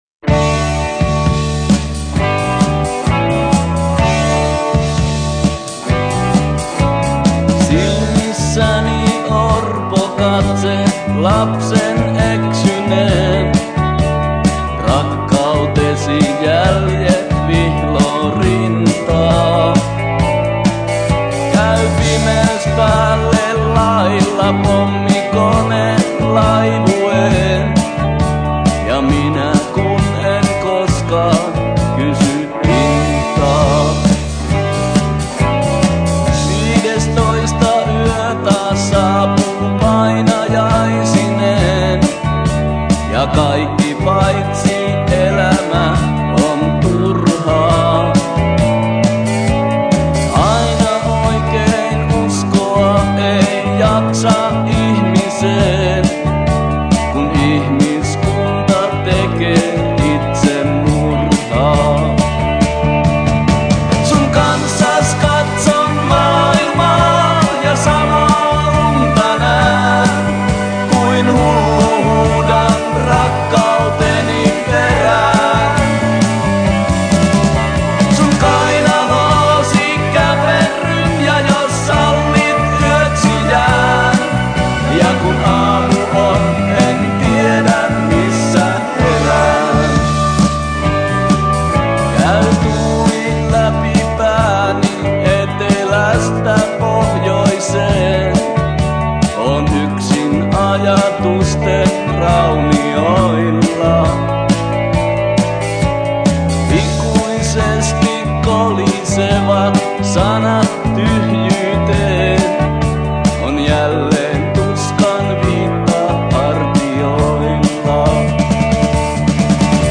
Er war Textschreiber, Komponist und Sänger.